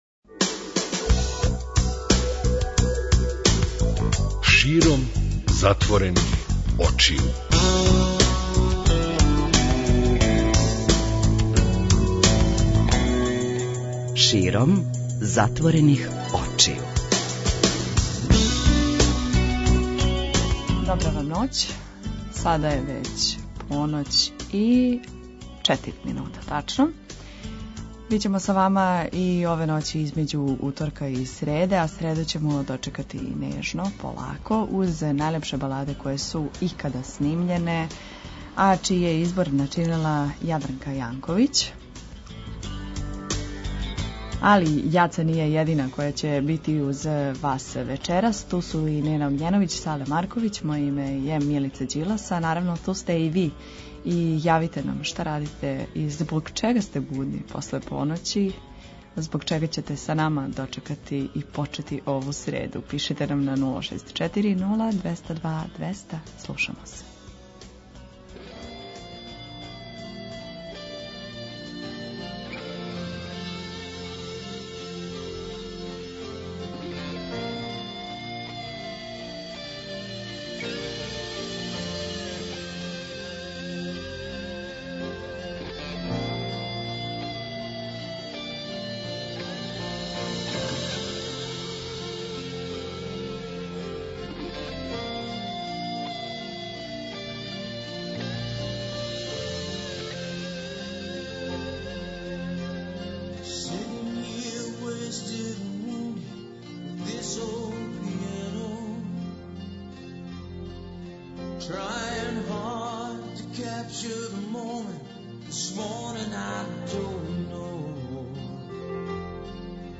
И ове ноћи између уторка и среде време проводимо ћаскајући и слушајући неке од најлепших балада које су икада снимљене.